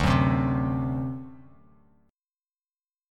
D7 Chord
Listen to D7 strummed